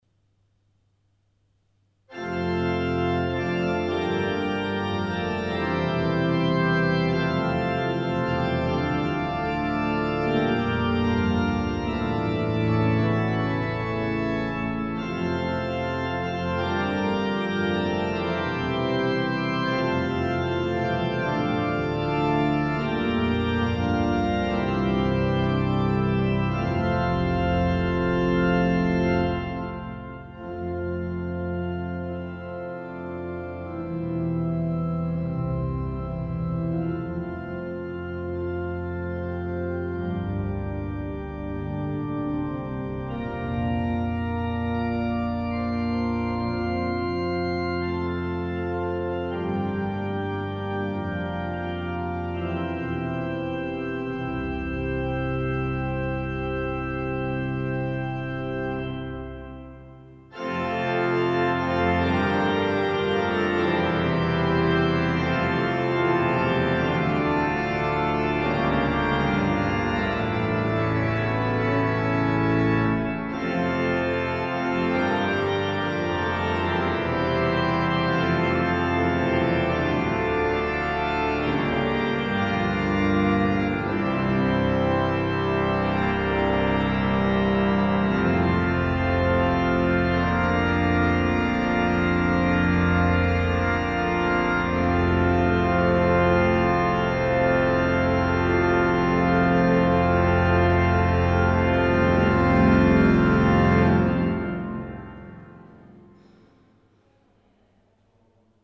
Rommet har flott akkustikk og gjør musikken magisk.
Det er en fantastisk varm og god klang i orgelet.
Det er dessverre en del støy i opptakene, men det var åpen kirke under mitt besøk. Det var også pågående restaureringsarbeider på kirkens fasade.
Luleå domkyrka   ZOOM H4n PRO 20.06.2023